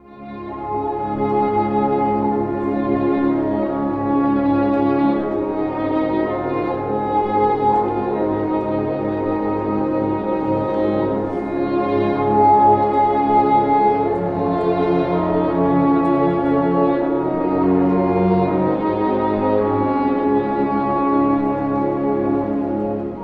↑古い録音のため聴きづらいかもしれません！（以下同様）
「Largo（幅広く、ゆるやかに）」の指示通り、静かで包み込むような雰囲気の楽章です。
冒頭は短い序奏。
続いて、イングリッシュホルン（コールアングレ）があの有名な旋律を奏でます。